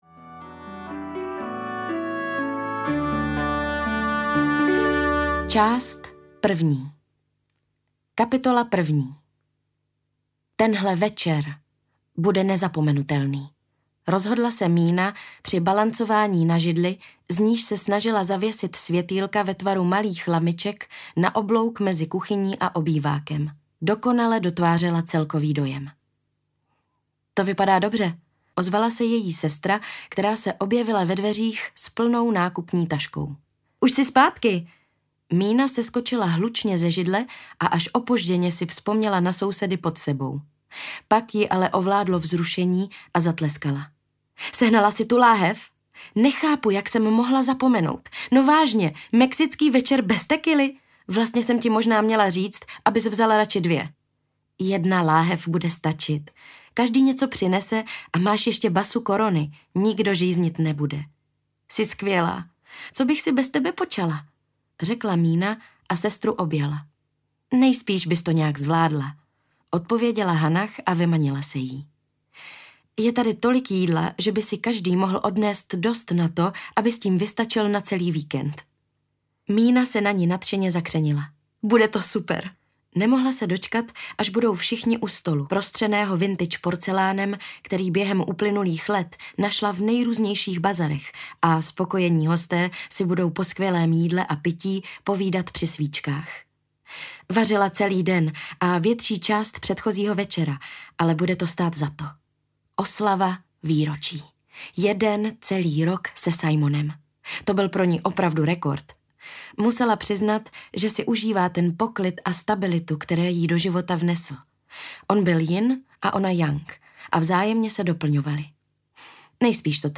Ukázka z knihy
chata-ve-svycarsku-audiokniha